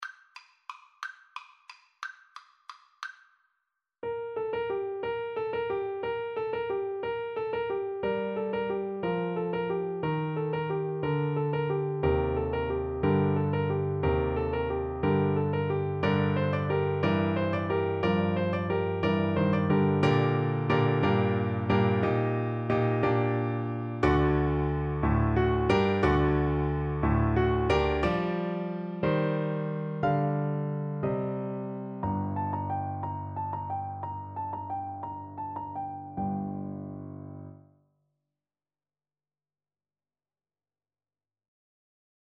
G minor (Sounding Pitch) (View more G minor Music for Bass Guitar )
Presto =c.180 (View more music marked Presto)
3/4 (View more 3/4 Music)
Traditional (View more Traditional Bass Guitar Music)